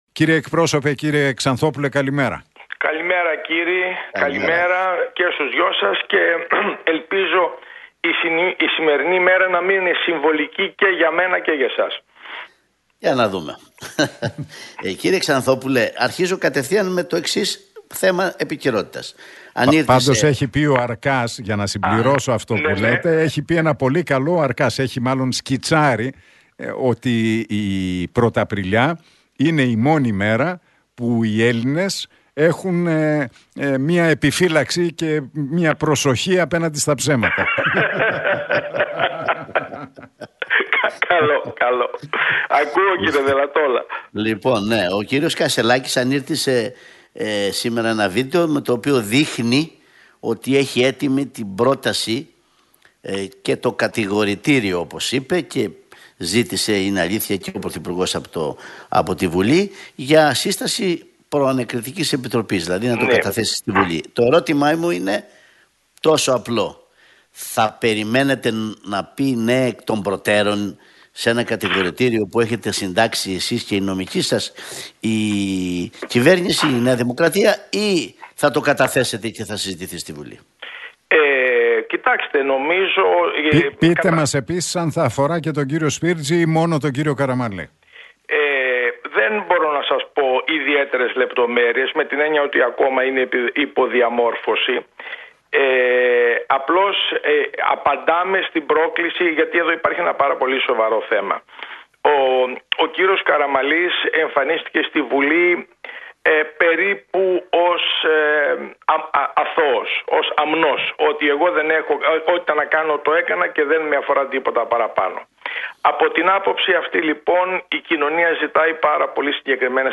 δήλωσε ο κοινοβουλευτικός εκπρόσωπος του ΣΥΡΙΖΑ, Θεόφιλος Ξανθόπουλος, μιλώντας στην εκπομπή του Νίκου Χατζηνικολάου